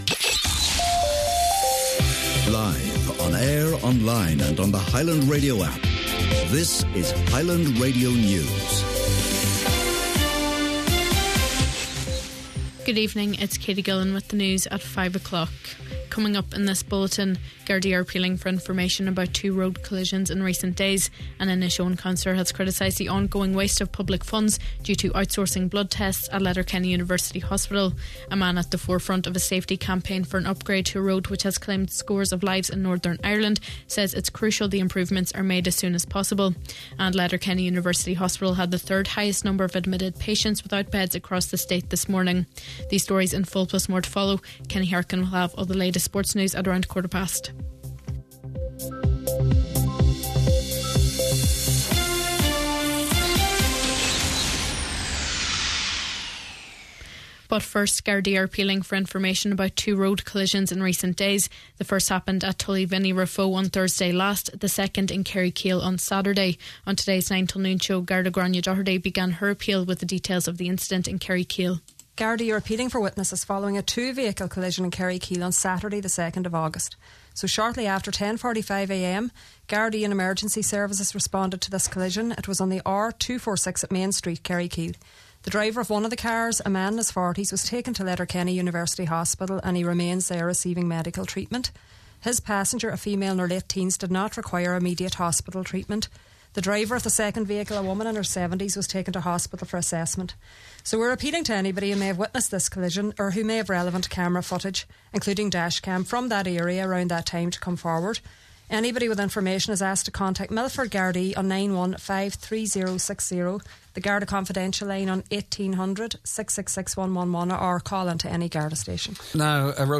Main Evening News, Sport and Obituary Notices – Tuesday August 5th